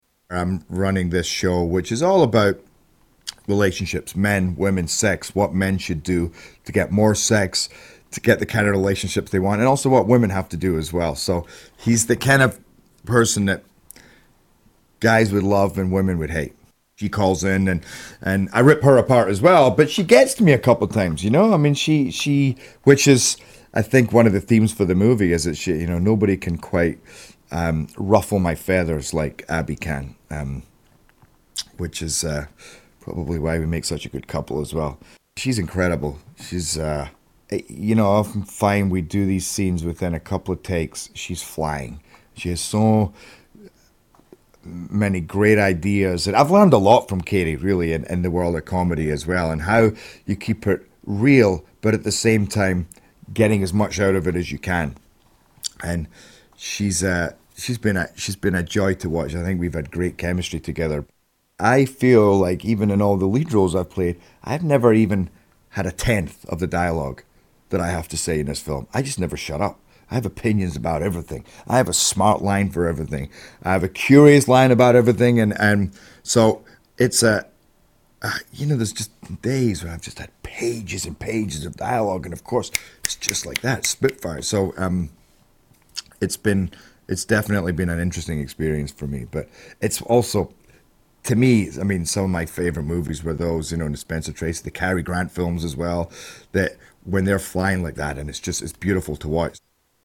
Gerard Butler Interview